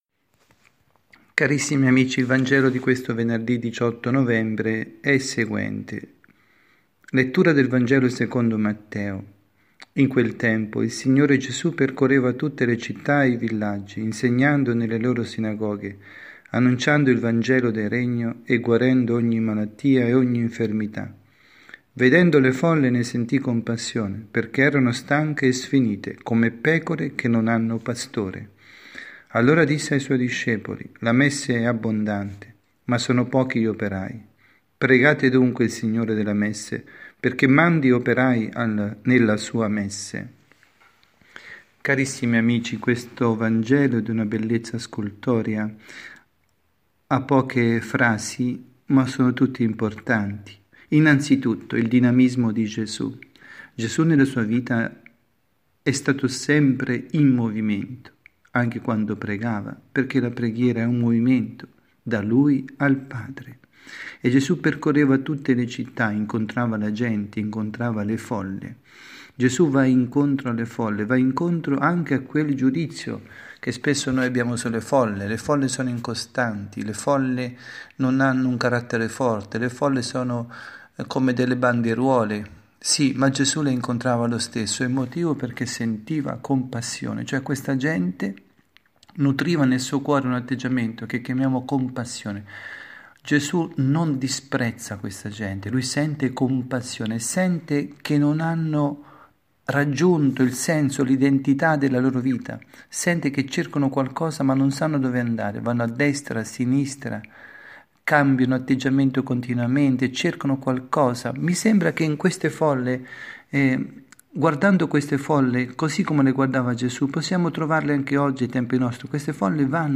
Catechesi
dalla Parrocchia S. Rita, Milano